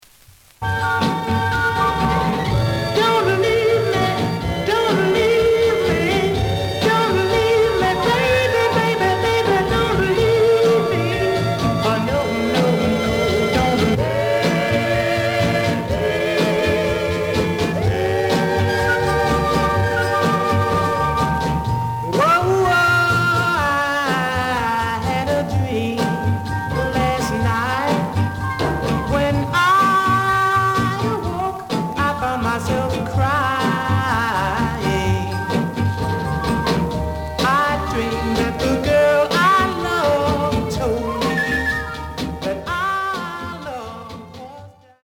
The audio sample is recorded from the actual item.
●Genre: Rhythm And Blues / Rock 'n' Roll
●Record Grading: VG (傷は多いが、プレイはまずまず。Plays good.)